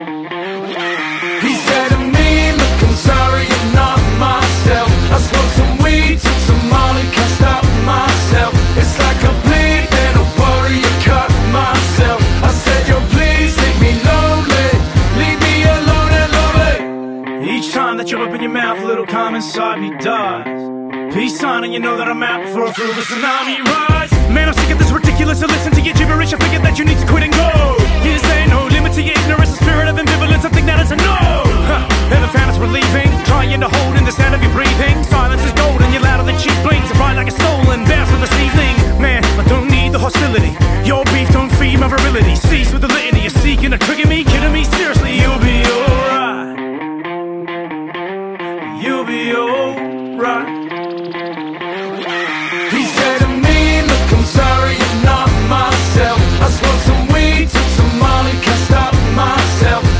📍- Big Bucket Car wash, Parafield